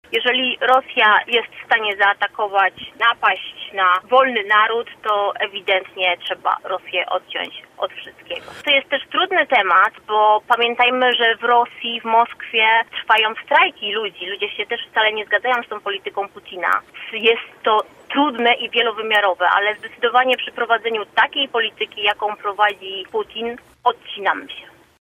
Wicewojewoda lubuska mówiła, że Rosja zaatakowała wolny kraj i musi ponieść dotkliwe konsekwencje: